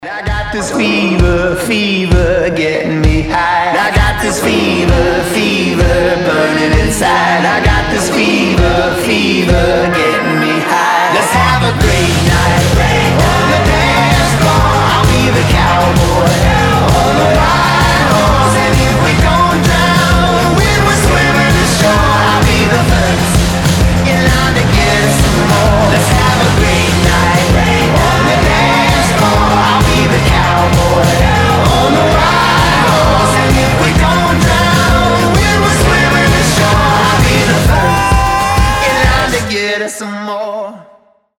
• Качество: 320, Stereo
мужской вокал
громкие
Alternative Rock
indie rock
христианский рок